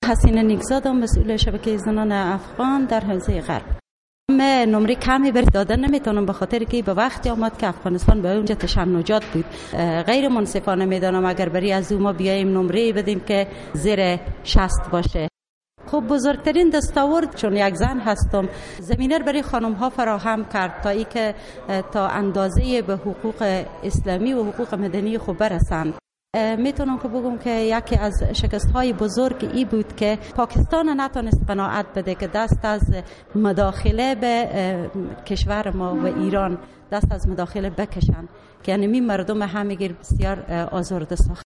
The URL has been copied to your clipboard No media source currently available 0:00 0:00:41 0:00 لینک دانلود | ام‌پی ۳ View on Hamid Karzai Legacy برای شنیدن مصاحبه در صفحۀ جداگانه اینجا کلیک کنید